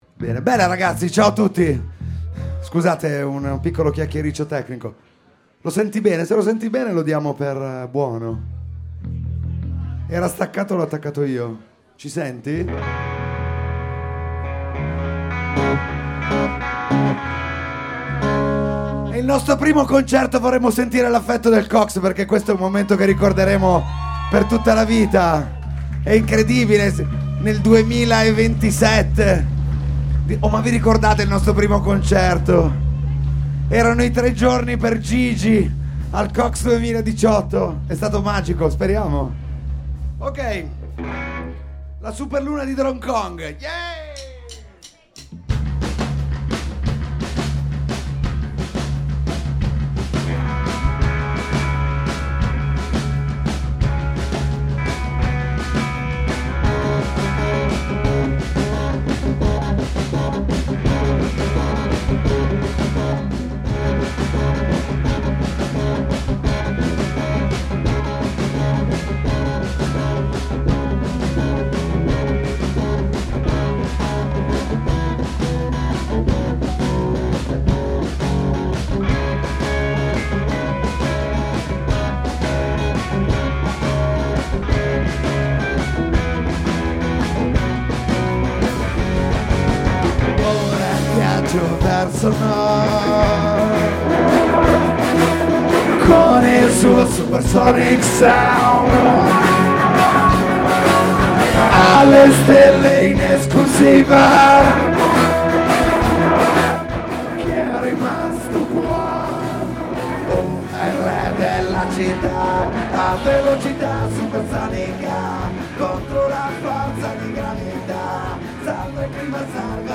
Live on stage